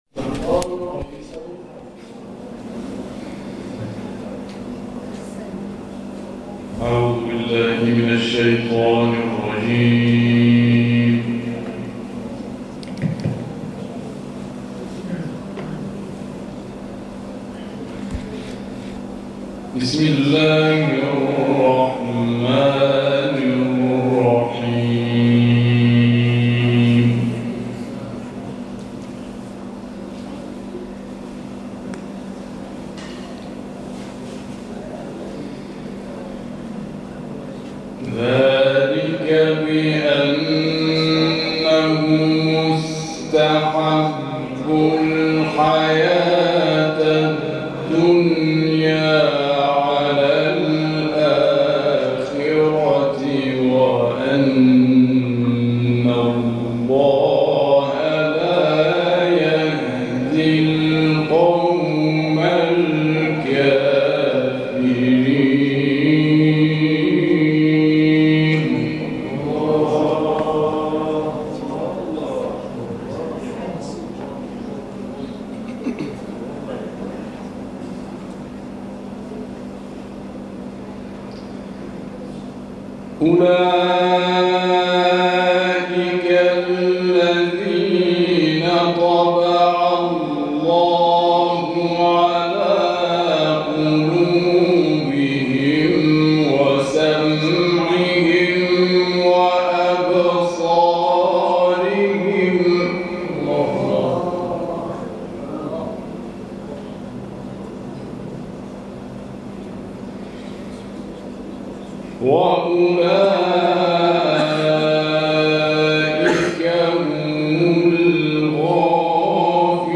تلاوت
این تلاوت در جلسه محله شیخعلی کلایه در شهر لاهیجان اجرا شده و مدت زمان آن 27 دقیقه است.